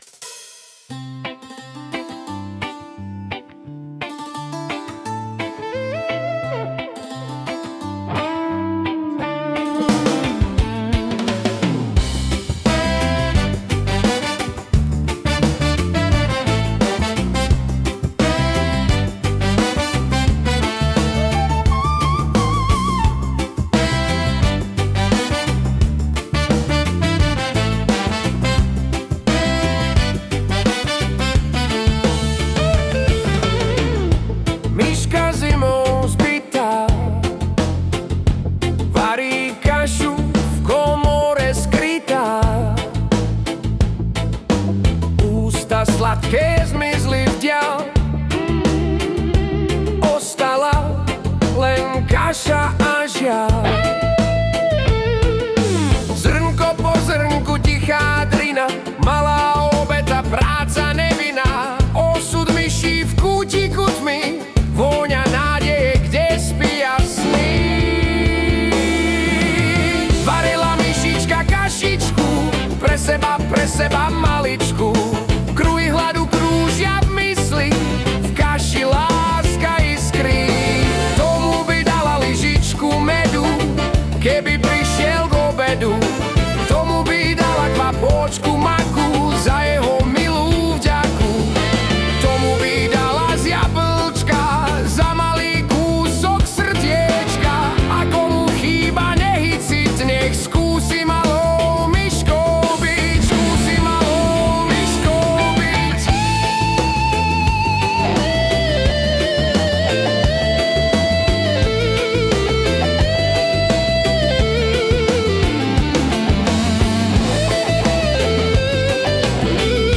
Hudba a spev AI